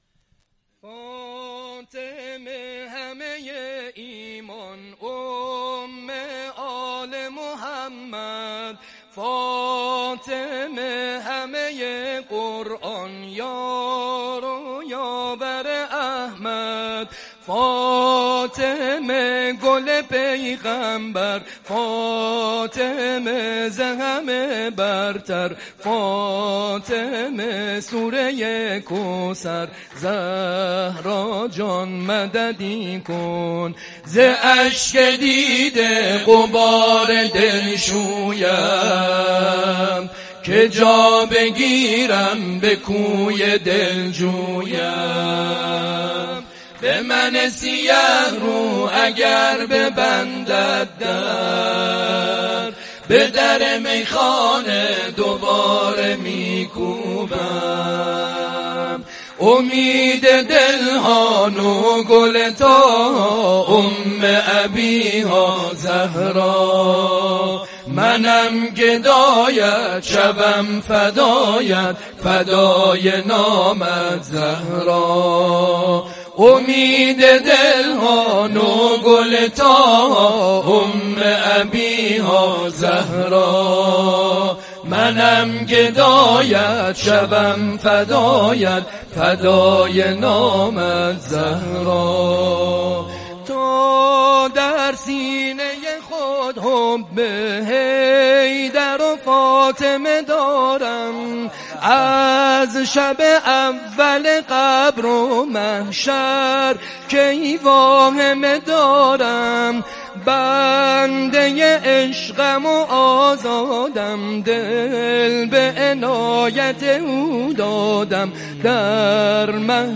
سرود-و-تواشیح.mp3